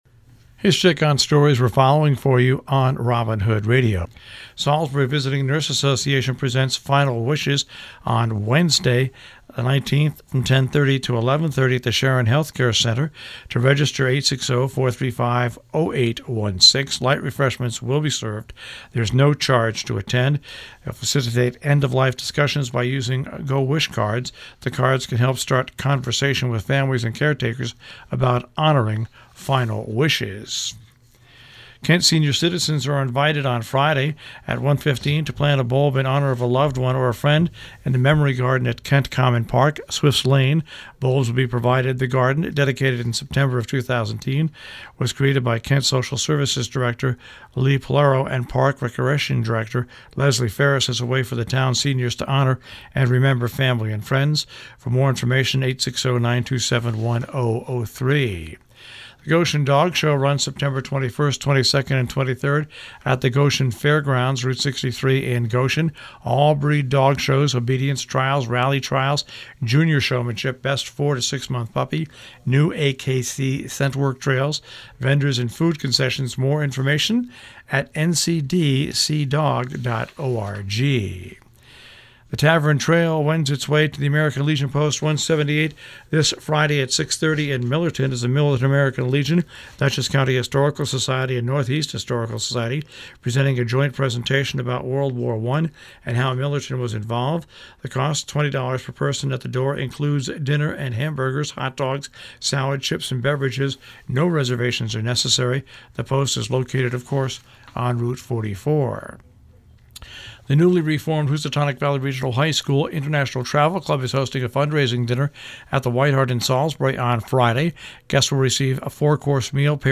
covers news and events in the Tri-State Region on Robin Hood Radio’s The Breakfast Club